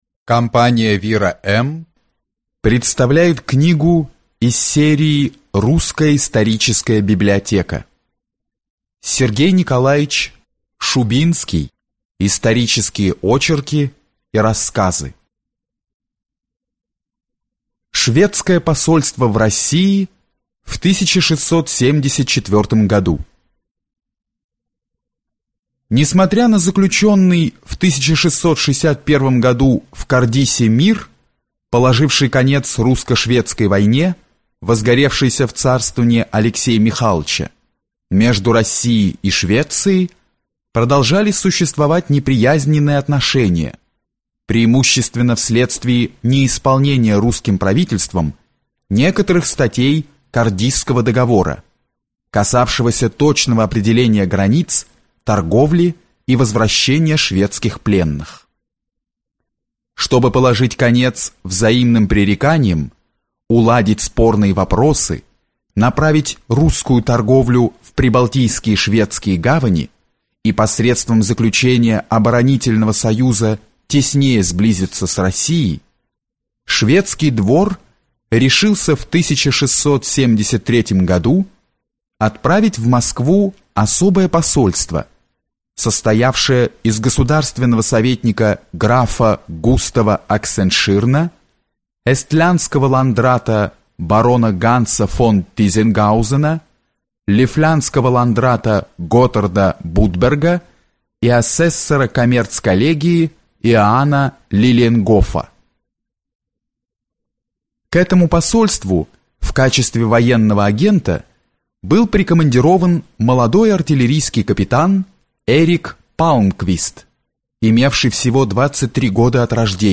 Аудиокнига Исторические очерки и рассказы | Библиотека аудиокниг